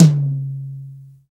Index of /90_sSampleCDs/Roland L-CD701/KIT_Drum Kits 7/KIT_Loose Kit
TOM ROUNDT07.wav